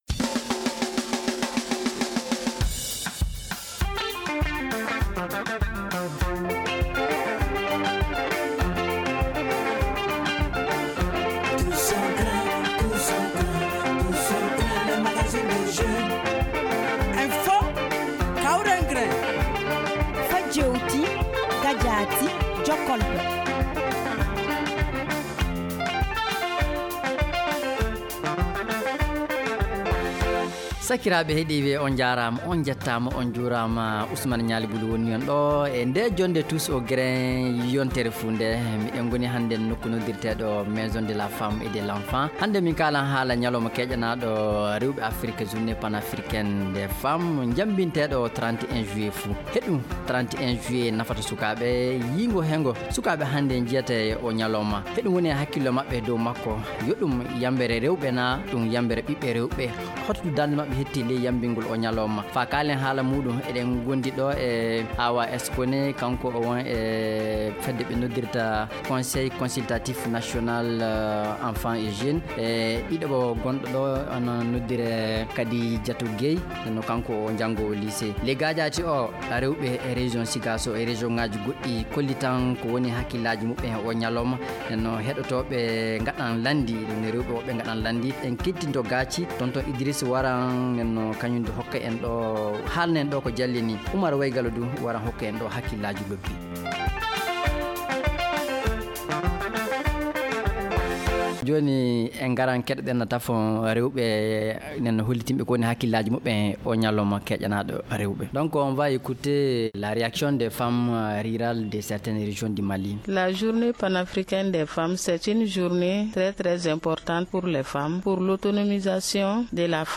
On a posé notre studio à la maison de la femme et de l’enfant pour échanger sur ces questions.